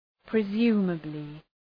Προφορά
{prı’zu:məblı}